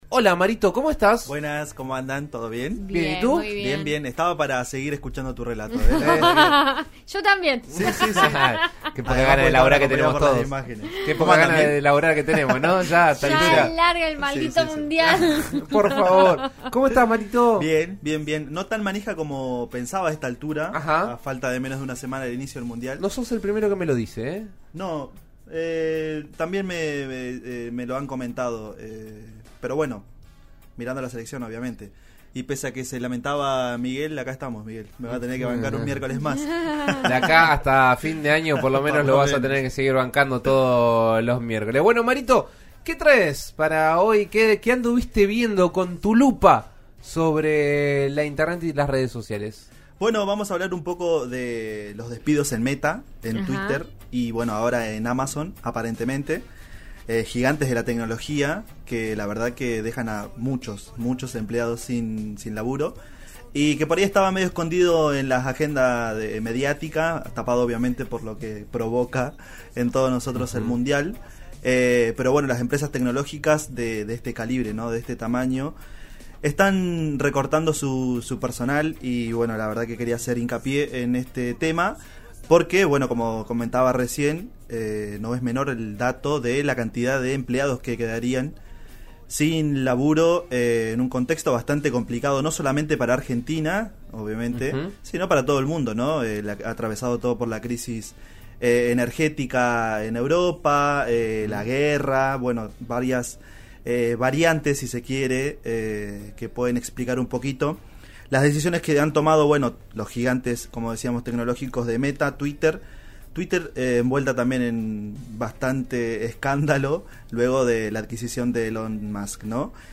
En La Lupa, la columna de En Eso Estamos de RN Radio, conversamos sobre el tema.